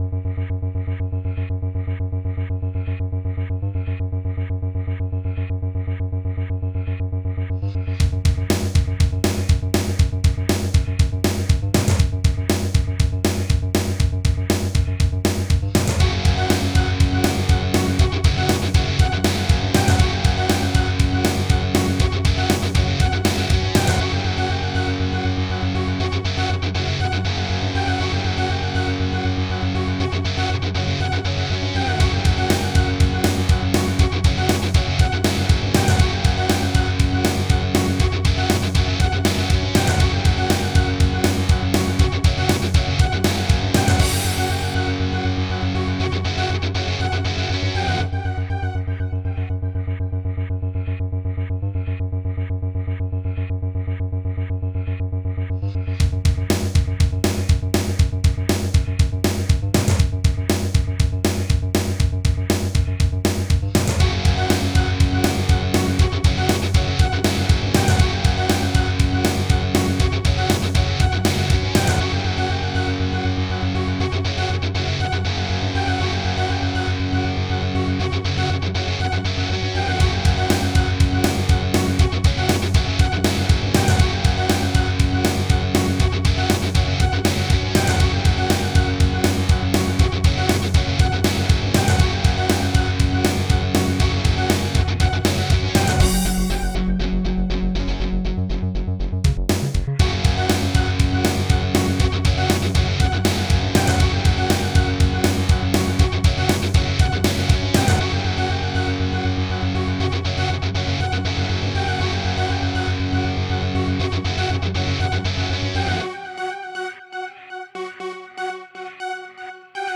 Lo-Fi/chiptune Techno Metal